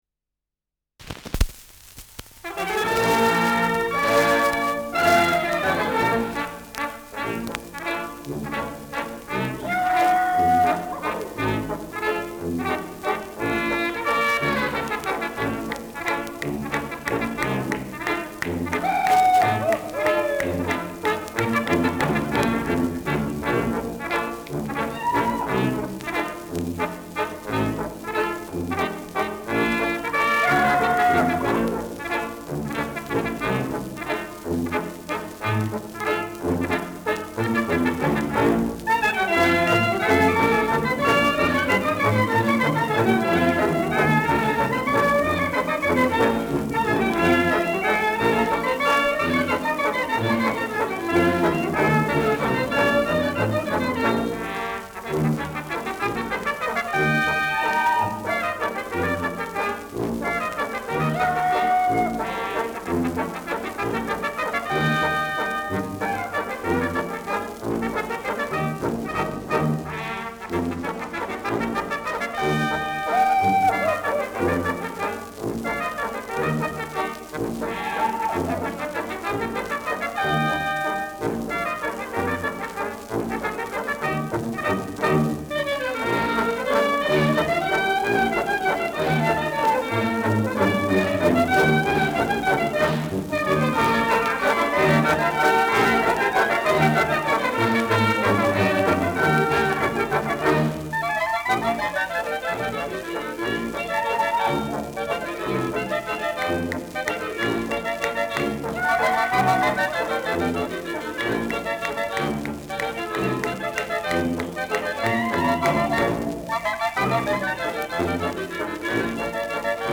Schellackplatte
Leicht abgespielt : Gelegentliches Knistern : Gelegentliches Knacken : Schwingender Pfeifton am Schluss
[Berlin] (Aufnahmeort)